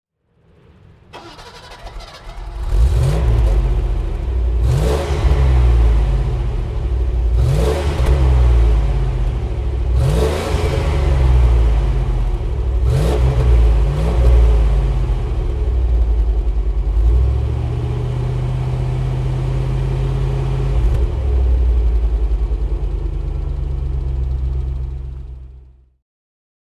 Range Rover 4 Door (1985) - Starten und Leerlauf